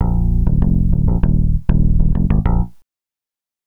Swinging 60s 1 Bass-A#.wav